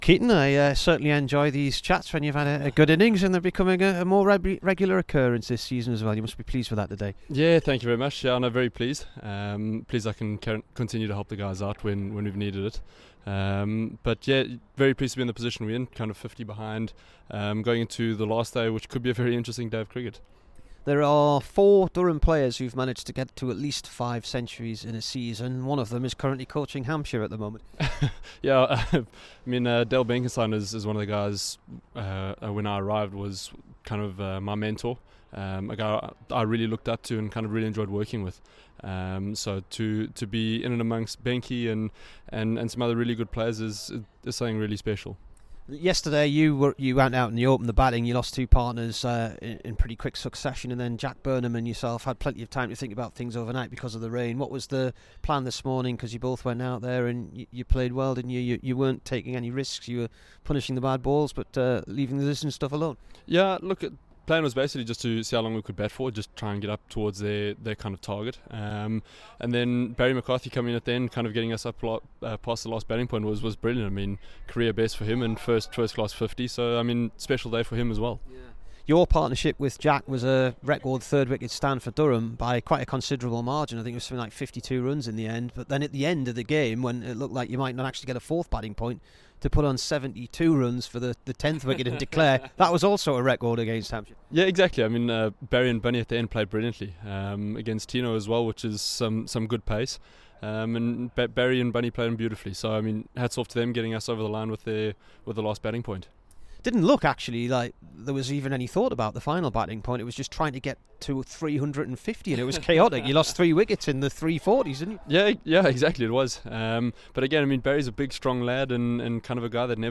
KEATON JENNINGS INTERVIEW